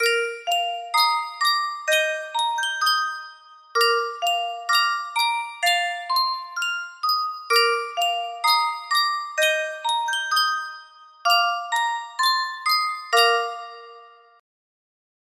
Sankyo Music Box - Nearer My God to Thee DLi music box melody
Full range 60